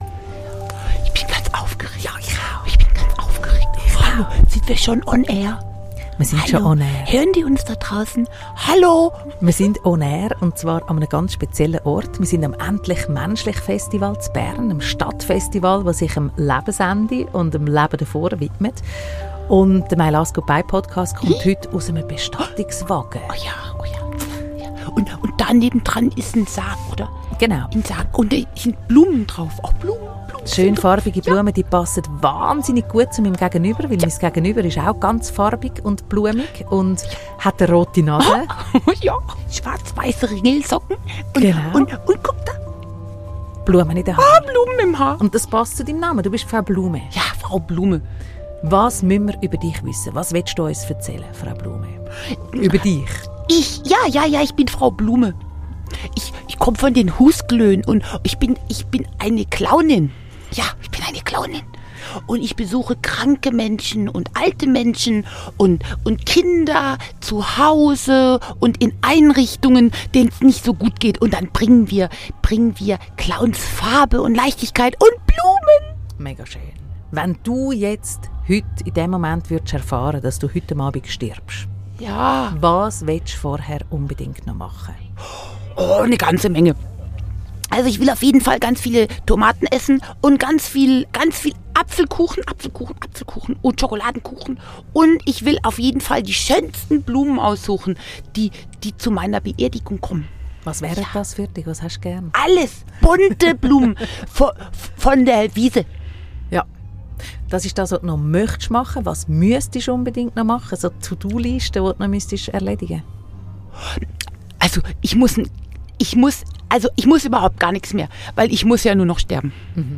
endlich.menschlich-Stadtfestivals mitten in Bern zu mir in den